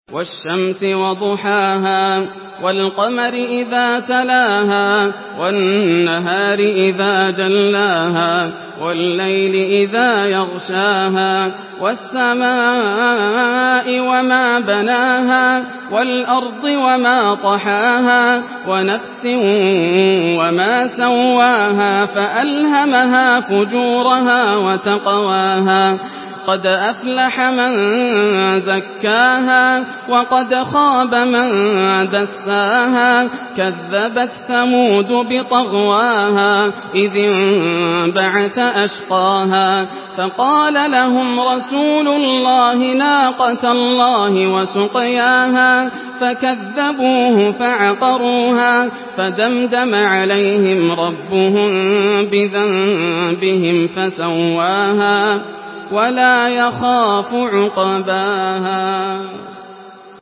Surah الشمس MP3 by ياسر الدوسري in حفص عن عاصم narration.
مرتل